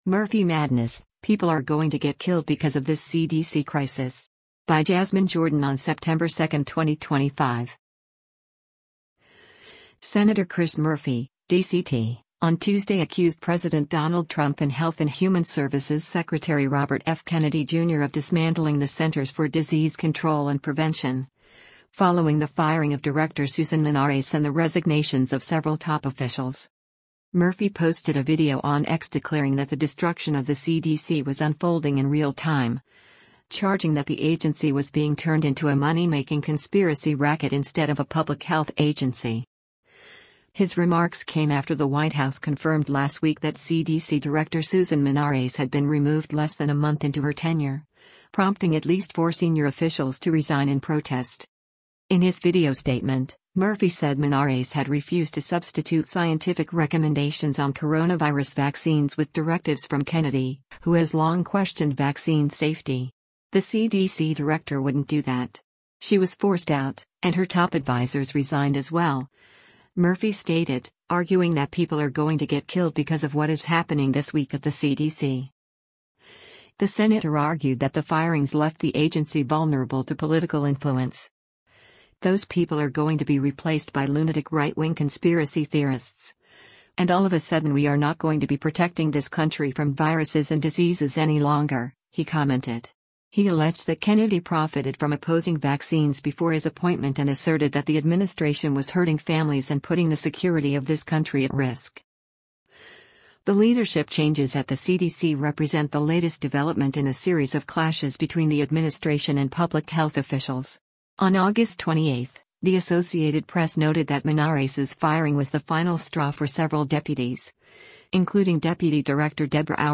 Murphy posted a video on X declaring that “the destruction of the CDC” was unfolding “in real time,” charging that the agency was being turned into “a money-making conspiracy racket instead of a public health agency.”